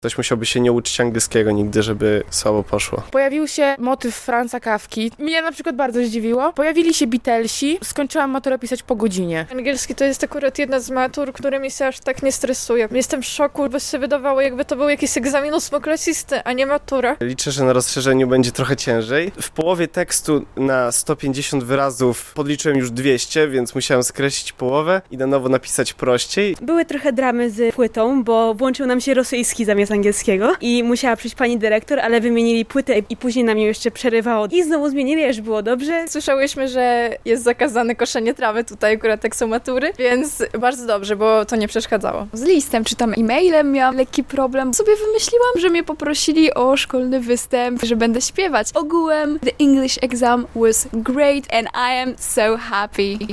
Słuchaj: Co białostoccy maturzyści uważają na temat tegorocznej matury z angielskiego? - relacja